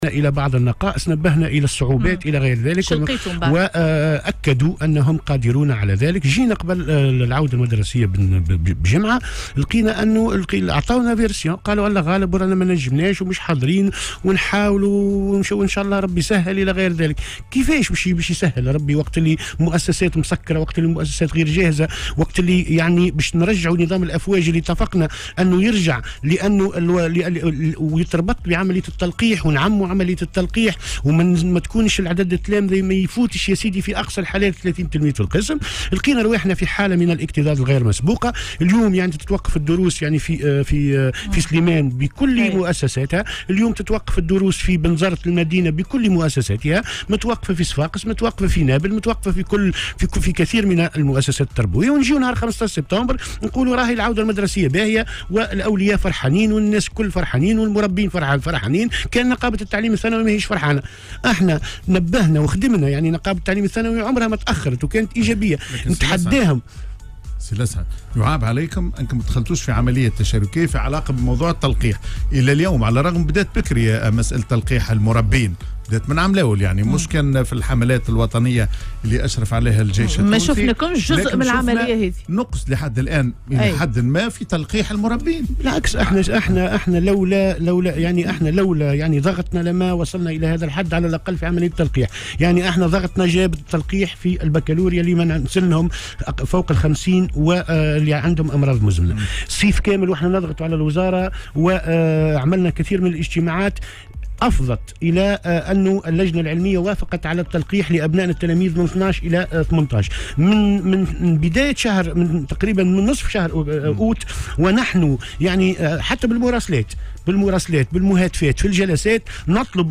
وقال في مداخلة له اليوم على "الجوهرة أف أم"، إن النقابة نبّهت في عديد المناسبات من بعض الإشكاليات التي تعاني منها هذه المؤسسات التربوية، لكن لم يتم تجاوزها.